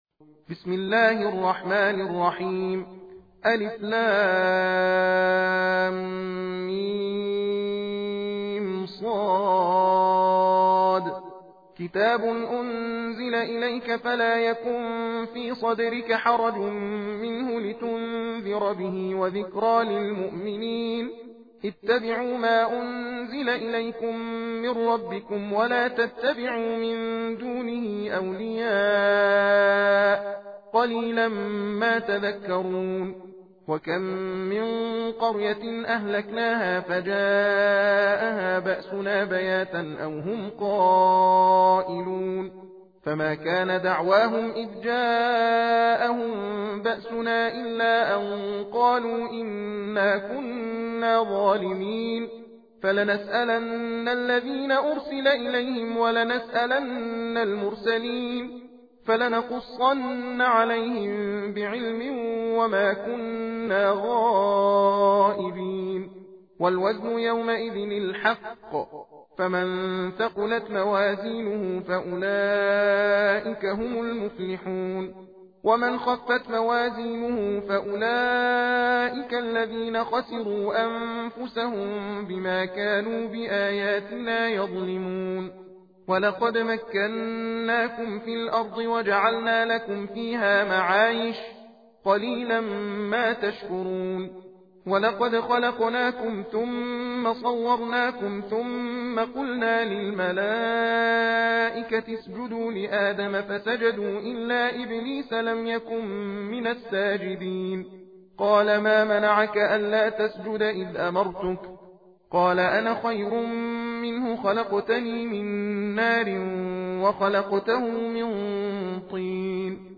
تندخوانی (تحدیر ) سوره اعراف + متن و ترجمه همراه با فضیلت سوره اعراف